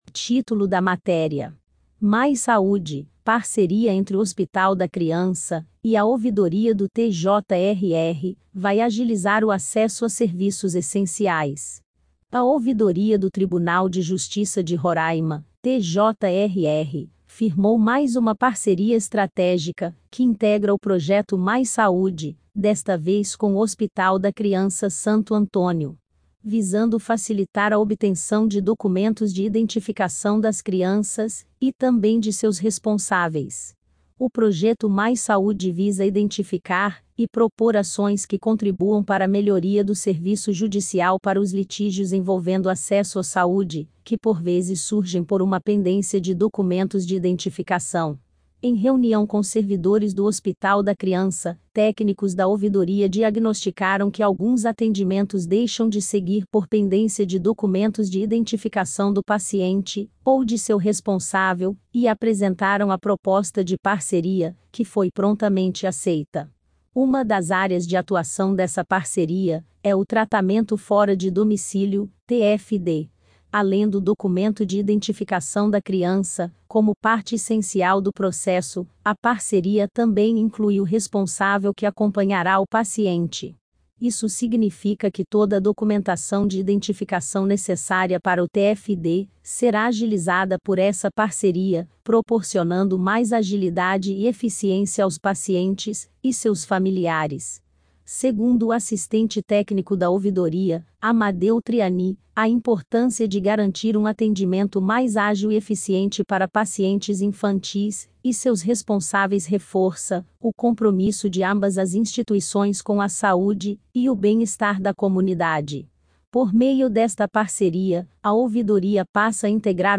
Acompanhe a matéria tambem em audio
MAIS_SADE_IA.mp3